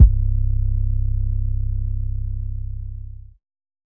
REDD 808 (10).wav